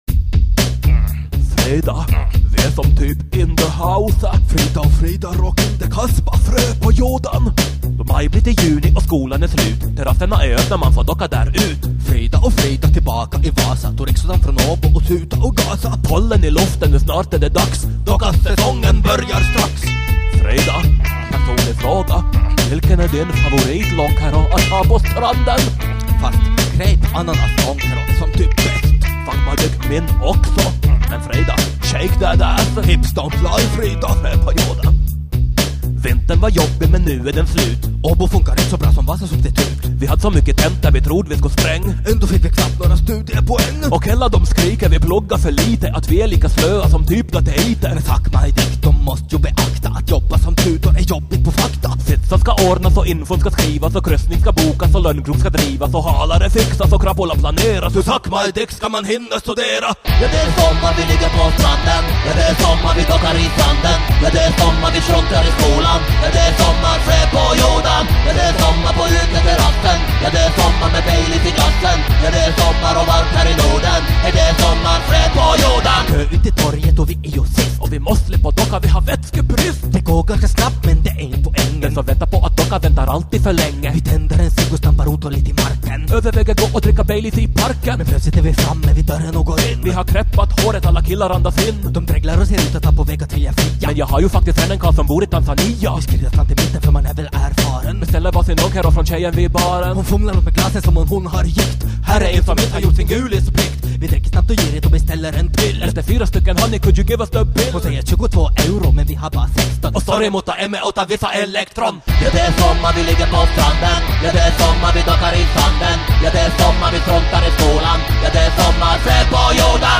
Dessutom i stereo!